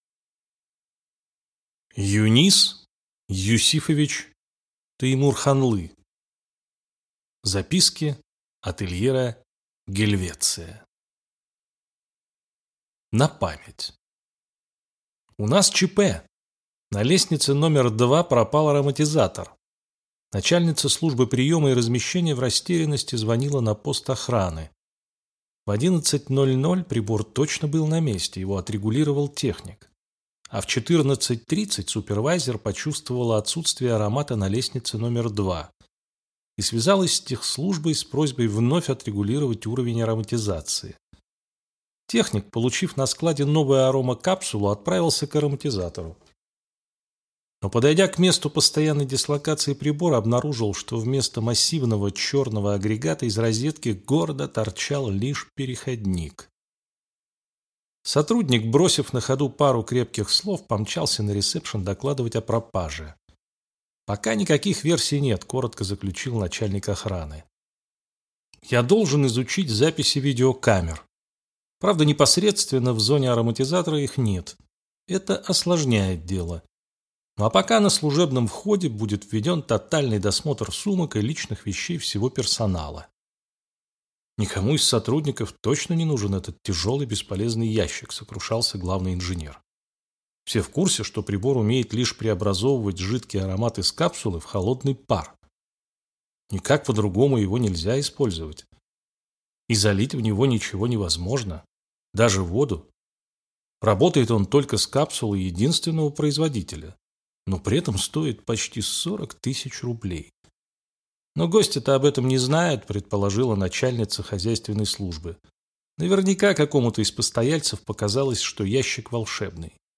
Аудиокнига «Гельвеция». Записки отельера | Библиотека аудиокниг